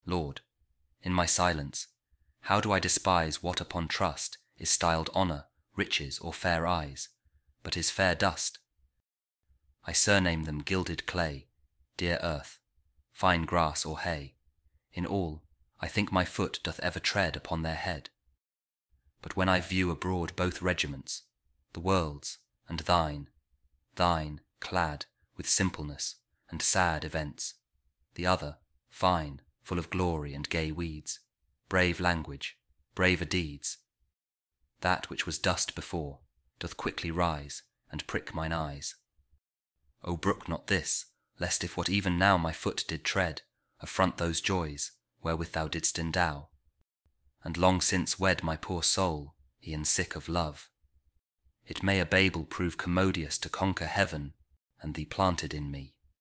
George Herbert | The Temple | Frailtie | Poem | Audio
george-herbert-temple-frailtie-poem-audio.mp3